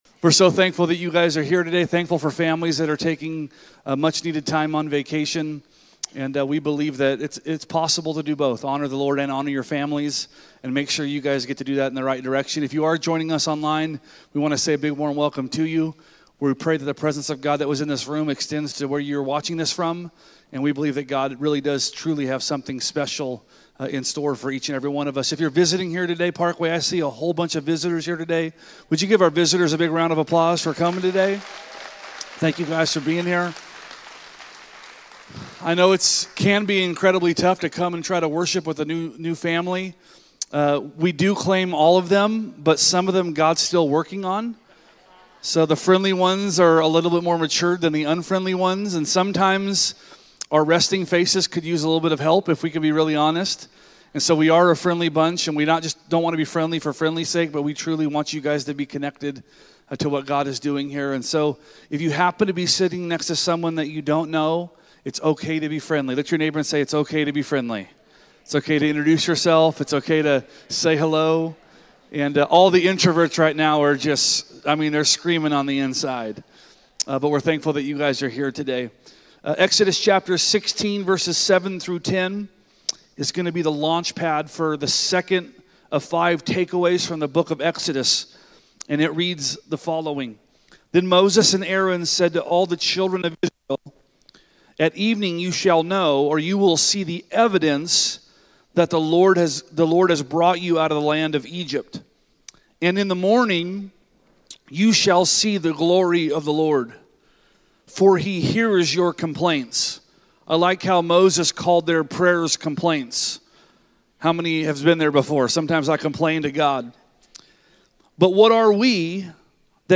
Sermons | Parkway Christian Center